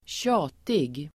Uttal: [²tj'a:tig]